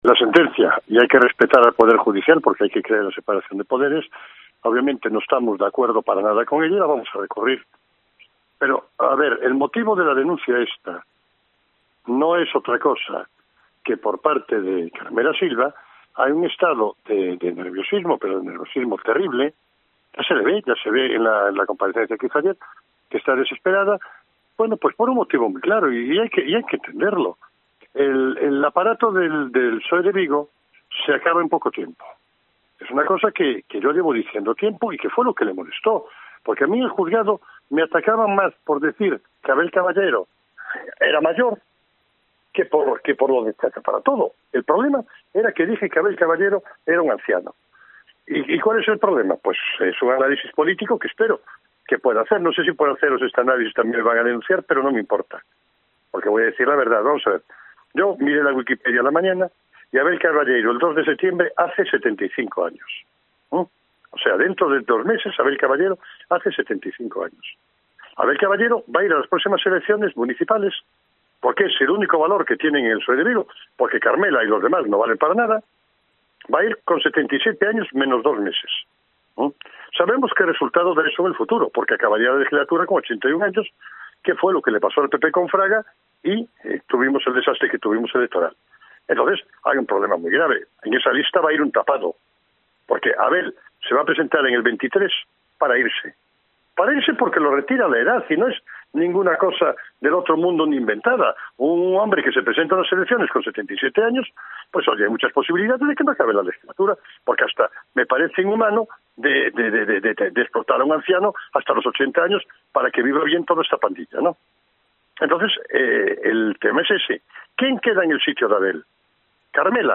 Declaraciones de Gonzalo Durán, alcalde de Vilanova de Arousa, en Cope